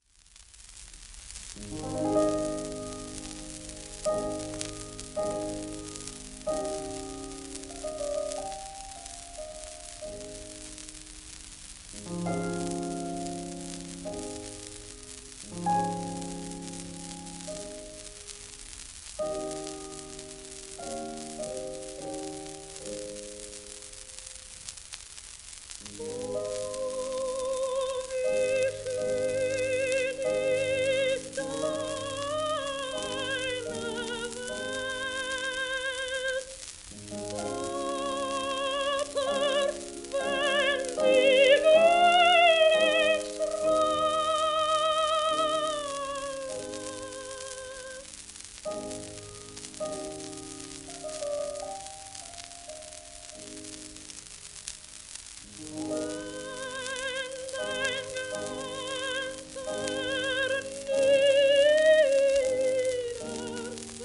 リア・ギンスター(Sop:1898-1985)
w/G.ムーア(p)
1934年録音